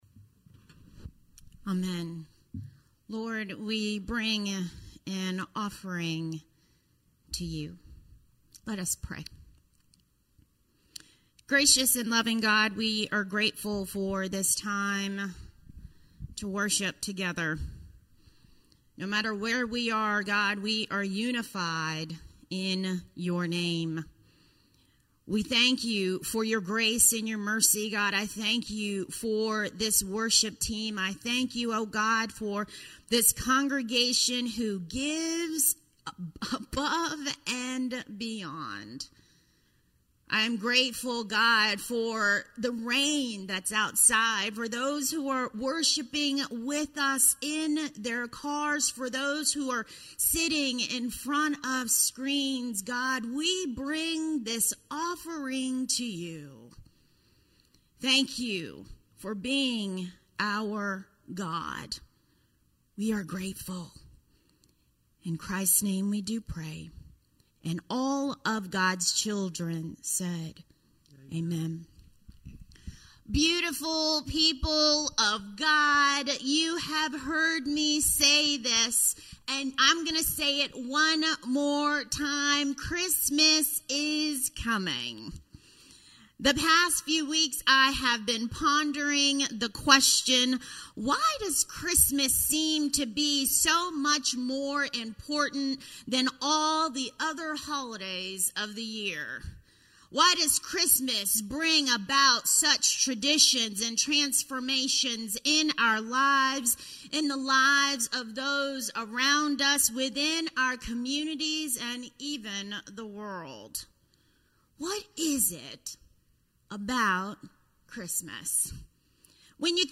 A message from the series "Seeking Jesus."